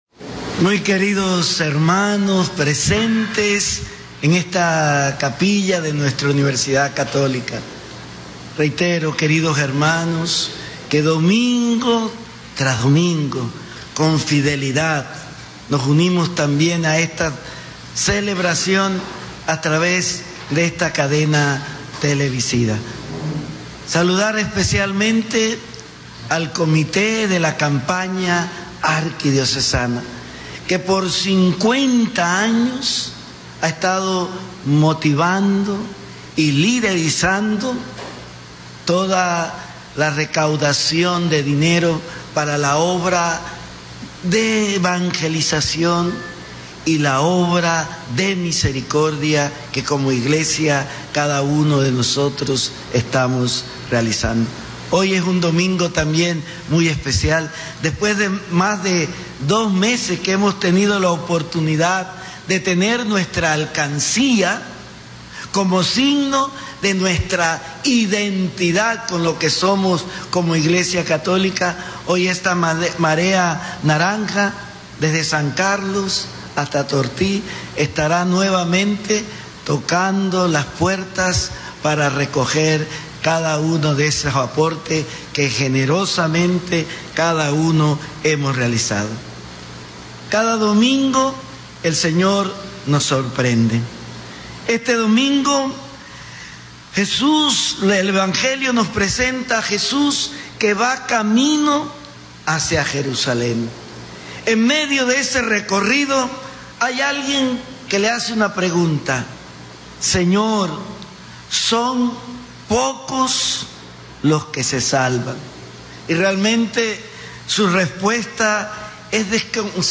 Homilía – Mons.
José Domingo Ulloa Mendieta OSA Capilla de la Universidad Católica Santa María de La Antigua Domingo 24 de agosto 2025